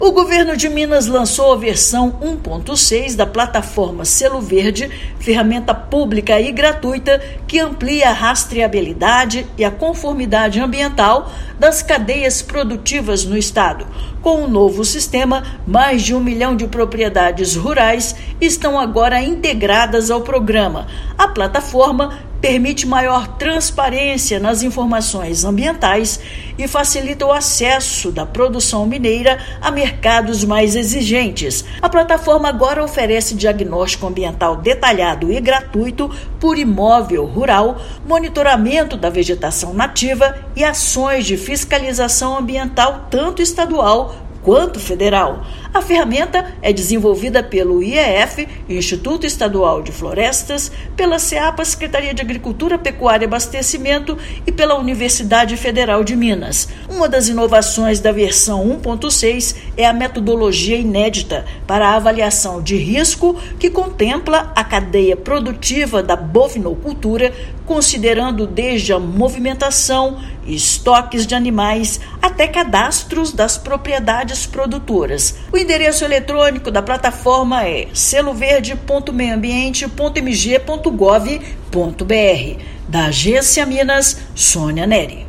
Atualização da plataforma oferece diagnóstico ambiental gratuito por imóvel rural, facilitando acesso da produção mineira a mercados mais exigentes. Ouça matéria de rádio.